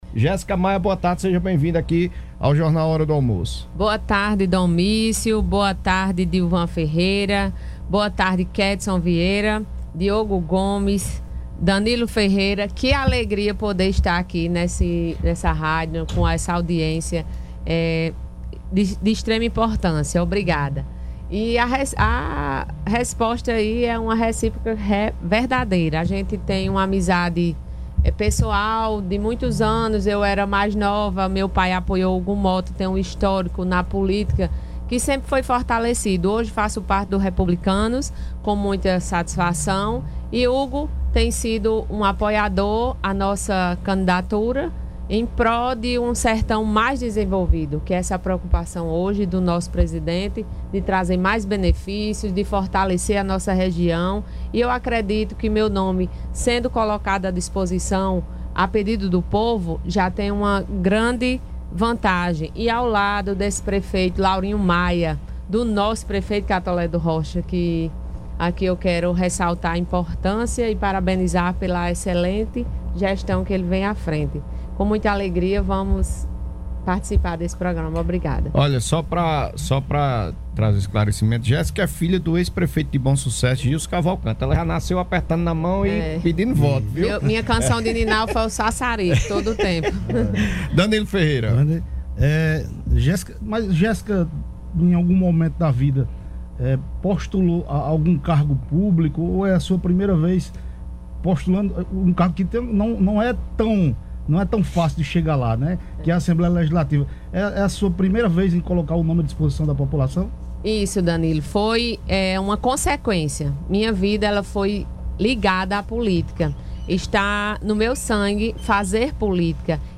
valores e projetos em entrevista na Rádio Solidária FM.